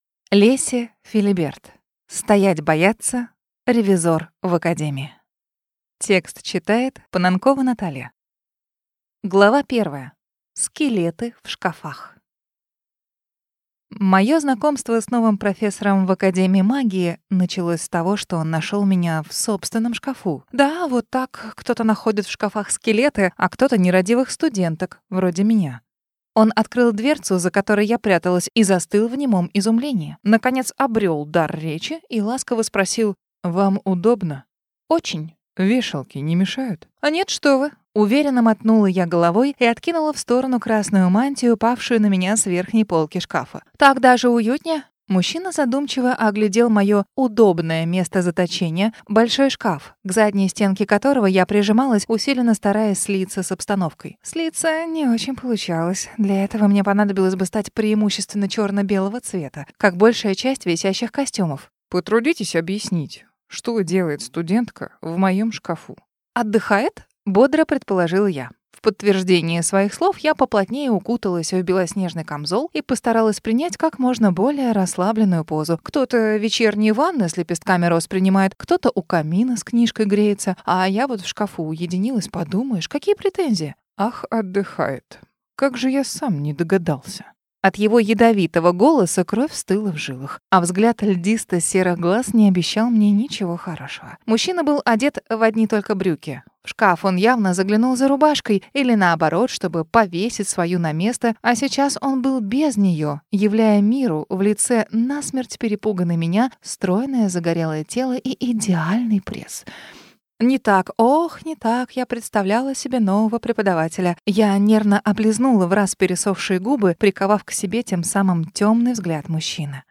Аудиокнига Стоять, бояться! Ревизор в академии | Библиотека аудиокниг